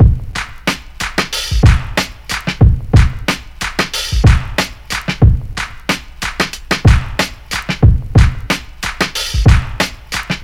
CL DrumLoop (92bpm).wav